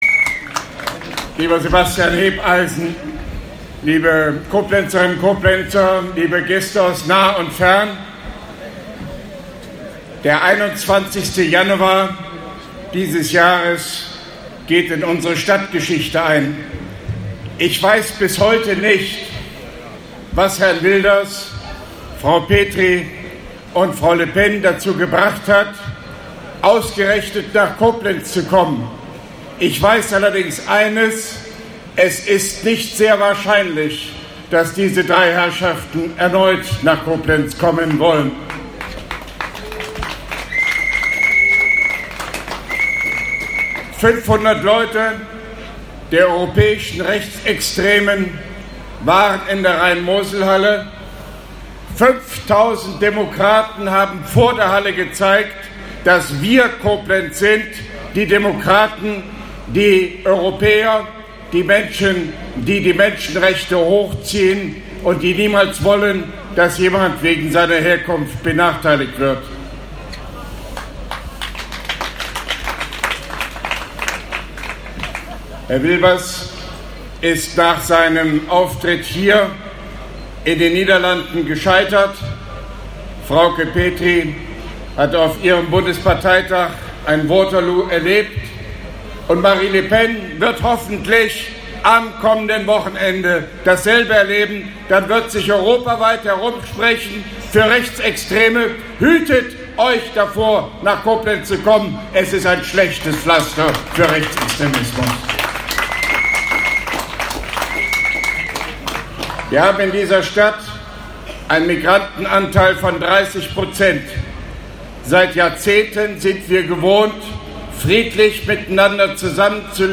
Grußwort von OB Hofmann-Göttig auf der Koblenzer DGB-Mai-Kundgebung, Koblenz 01.05.2017
dgbkundgebung-1.mp3